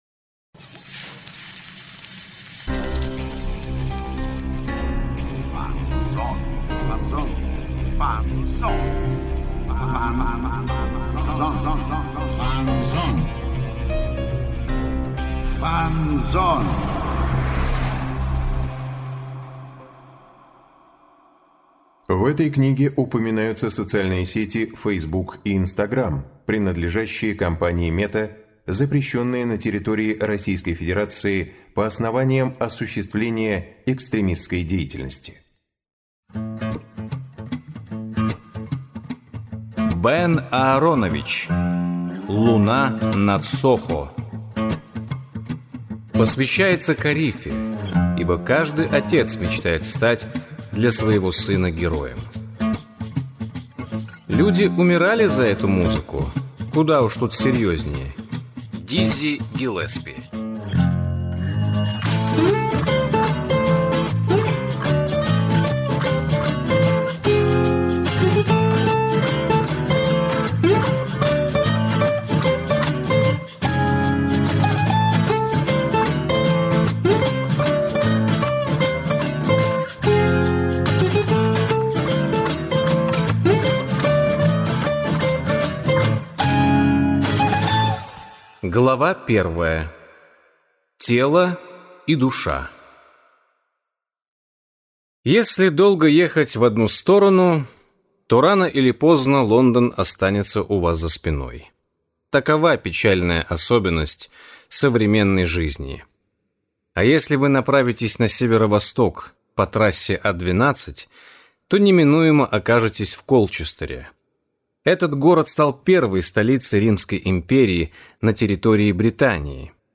Аудиокнига Луна над Сохо | Библиотека аудиокниг